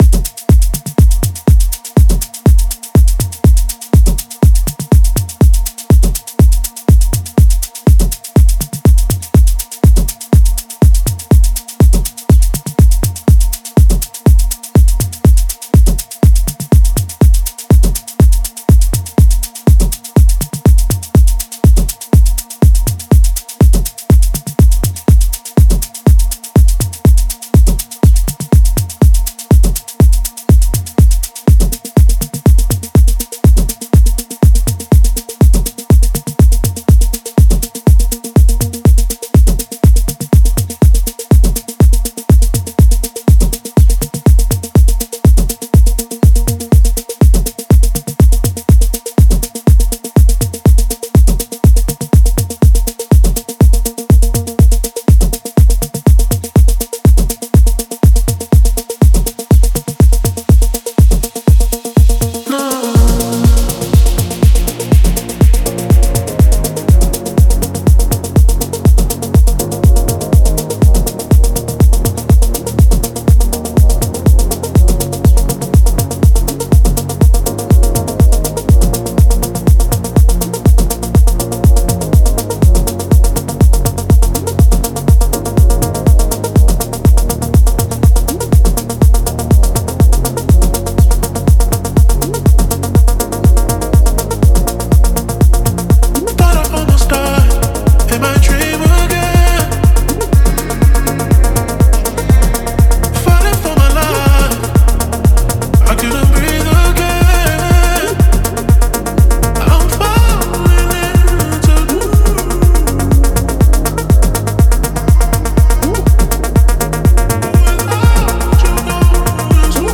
• Жанр: Dance, House